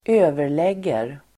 Uttal: [²'ö:ver_leg:er]